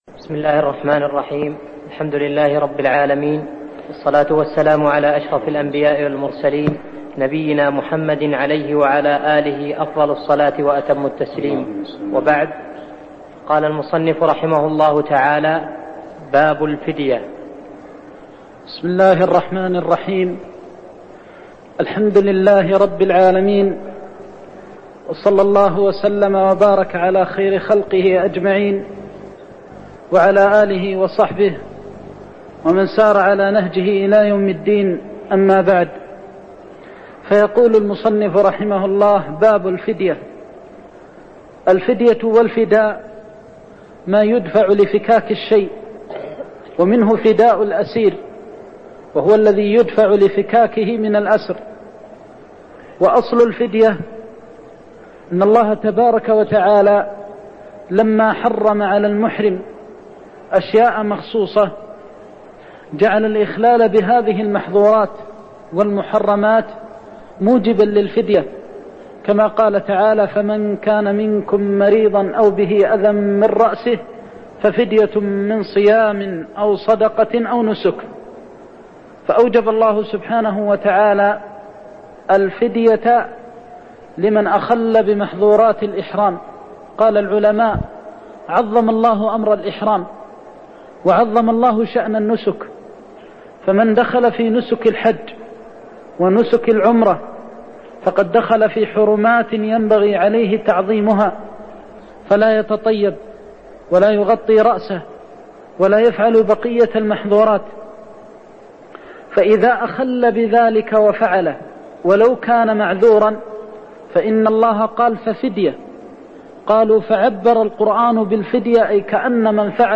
تاريخ النشر ٢٠ شوال ١٤١٧ هـ المكان: المسجد النبوي الشيخ